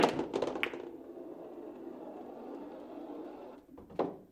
billard_kugel_loch.mp3